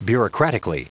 Prononciation du mot bureaucratically en anglais (fichier audio)